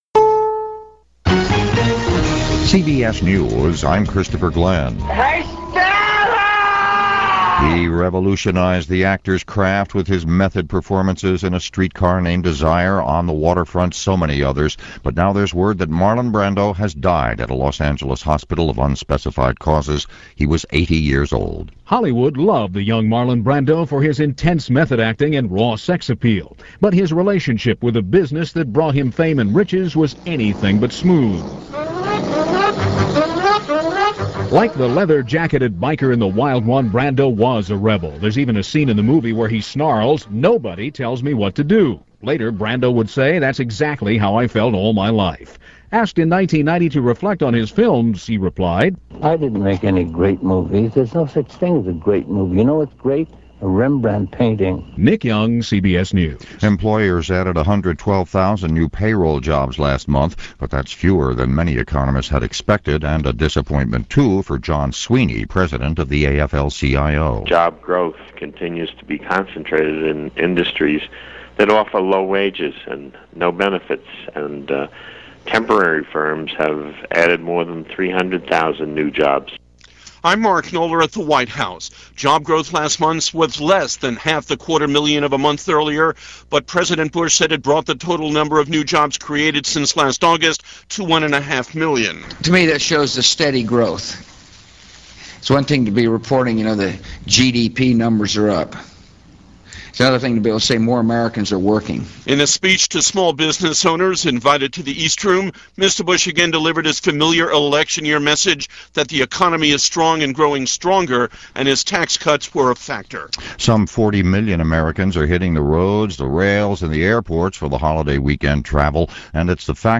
In addition to this newscast from CBS Radio, a newscast from BBC Radio 4 gives some idea of the international influence the actor had.
And that’s a little of what went on, this July 2, 2004 as reported by CBS Radio News and BBC Radio 4.